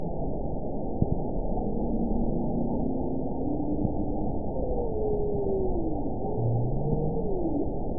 event 921988 date 12/24/24 time 11:08:00 GMT (5 months, 4 weeks ago) score 7.11 location TSS-AB04 detected by nrw target species NRW annotations +NRW Spectrogram: Frequency (kHz) vs. Time (s) audio not available .wav